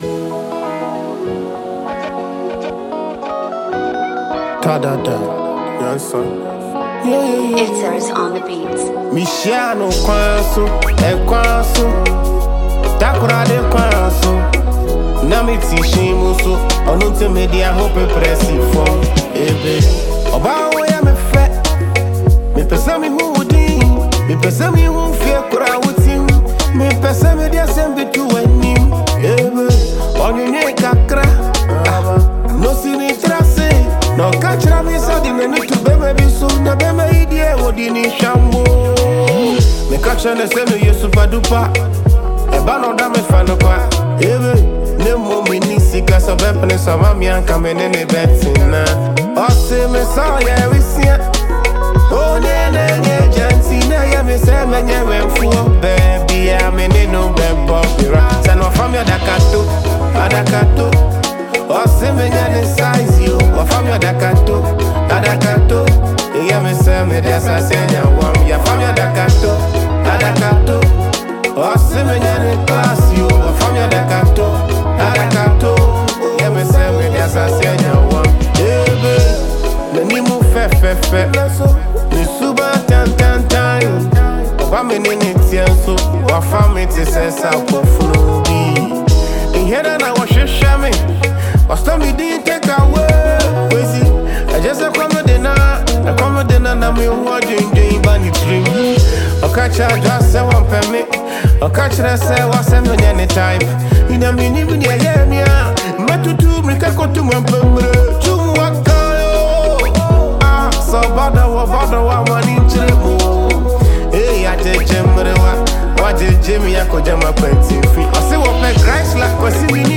guitarist and producer